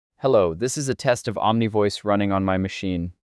00_auto_en — auto 모드, 영어
영어(00_auto_en)는 꽤 자연스러운데, 한국어(01_auto_ko)는 톤이 많이 평평하고 억양이 어색한 느낌이 있어요. voice design 쪽은 female_british, male_low, whisper 모두 지정한 캐릭터가 어느 정도 구분돼 들리긴 해요.
AI, TTS